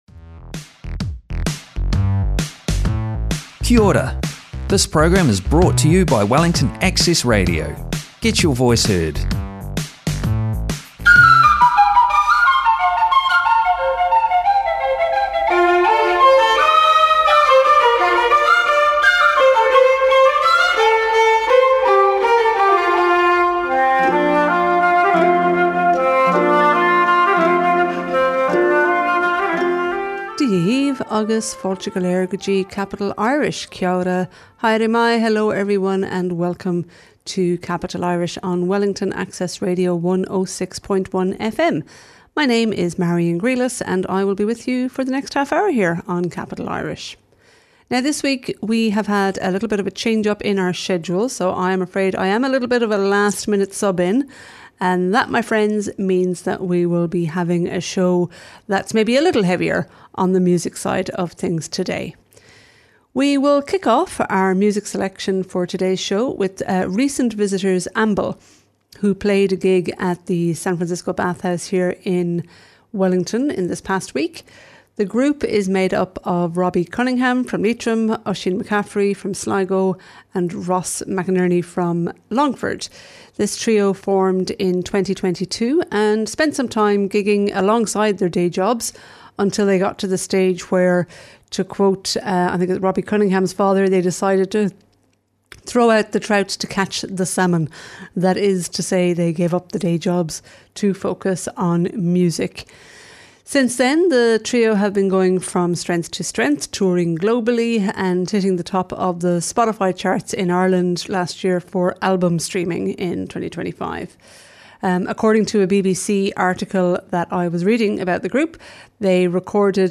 The programme includes Irish news, local events and activities with an Irish flavour as well as interviews and live music from visiting Irish musicians. It also includes book and movie reviews and on occasion some political views/reviews.